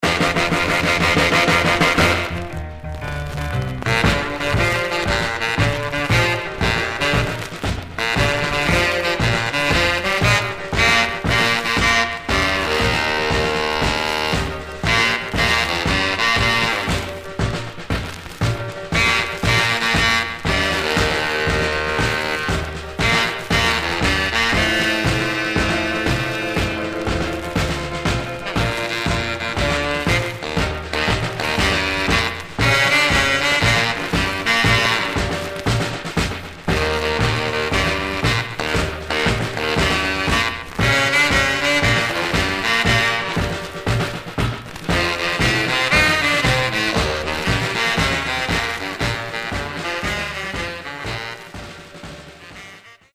Stereo/mono Mono
R&B Instrumental